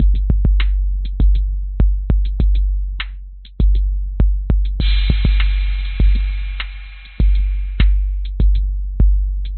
描述：节拍循环
标签： 808 节拍 节拍 hip_hop trip_hop
声道立体声